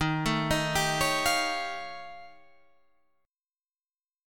D# 9th Flat 5th